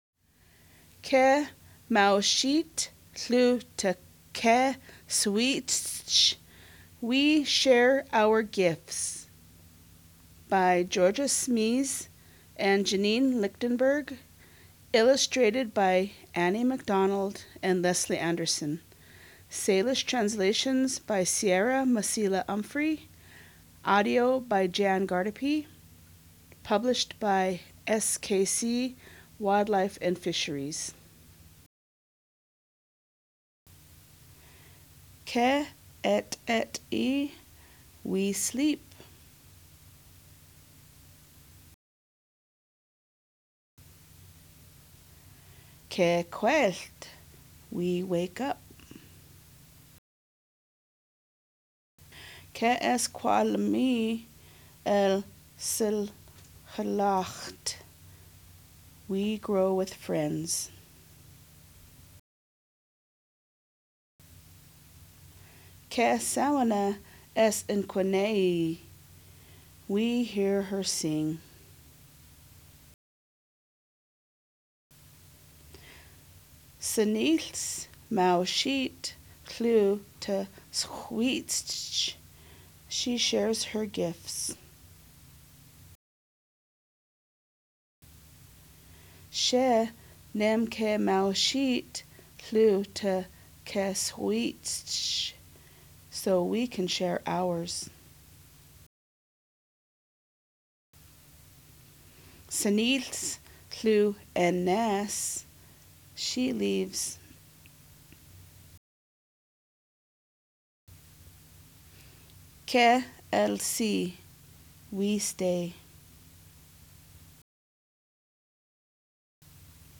The books are illustrated by local artists, written by the SKC Wildlife and Fisheries staff, and translated and narrated by Tribal Members of the Confederated Salish and Kootenai Tribes.